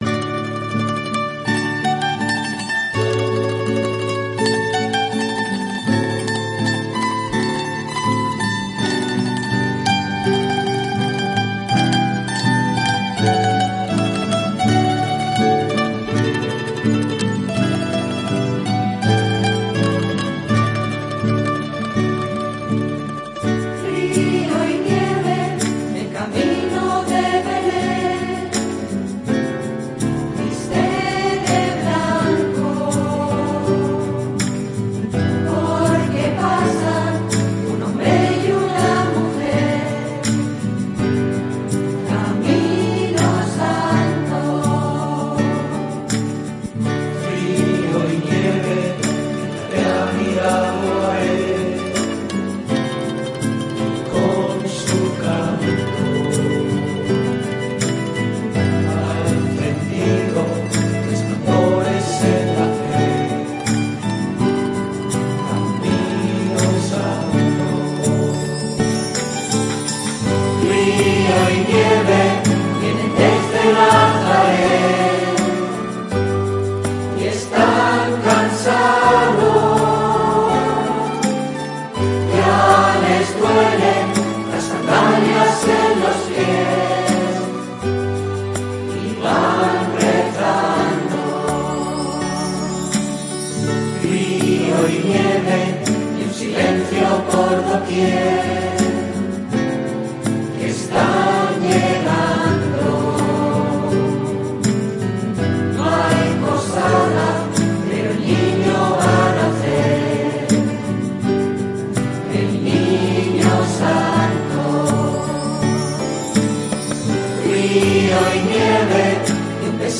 Invitación al tradicional concierto de villancicos del coro de la parroquia